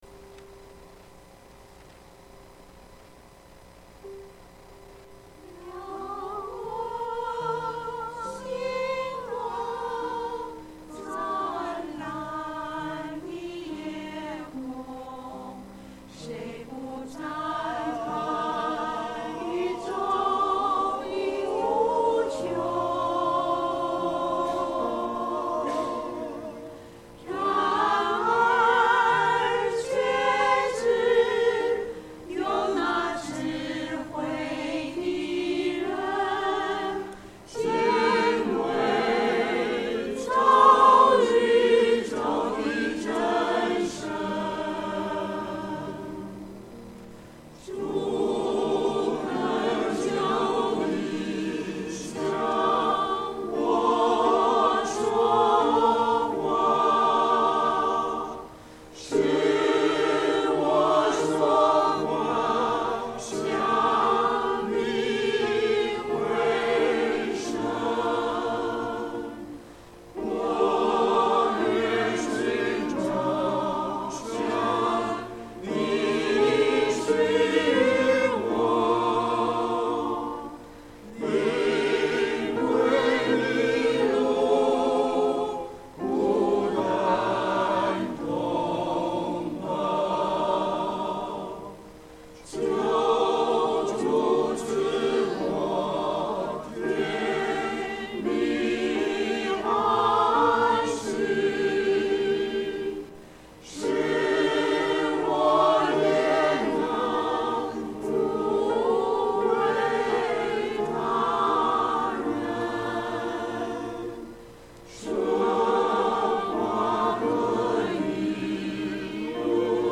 • 詩班獻詩